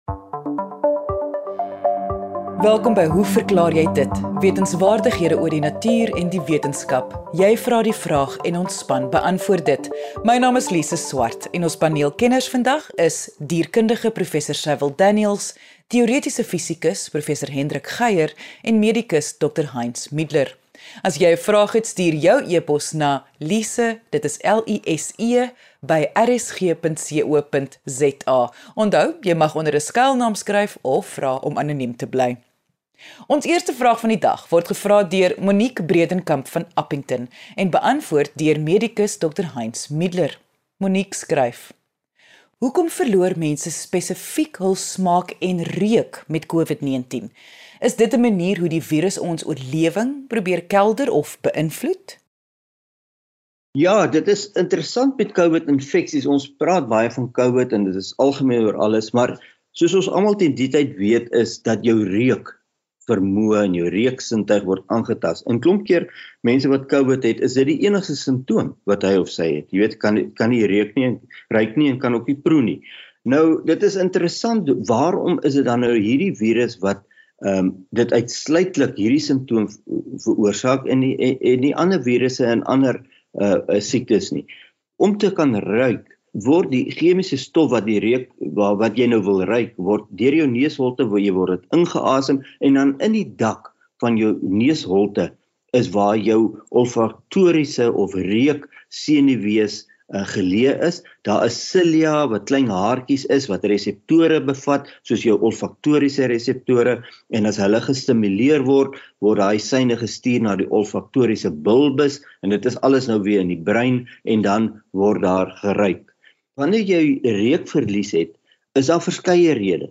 Die paneel kenners leer ons meer van ons reuksintuie; die teepot-effek (daai lastige druppel op die teepot se tuit); hoekom sommige diere maak asof hulle dood is; en die belang van die hioïedbeen.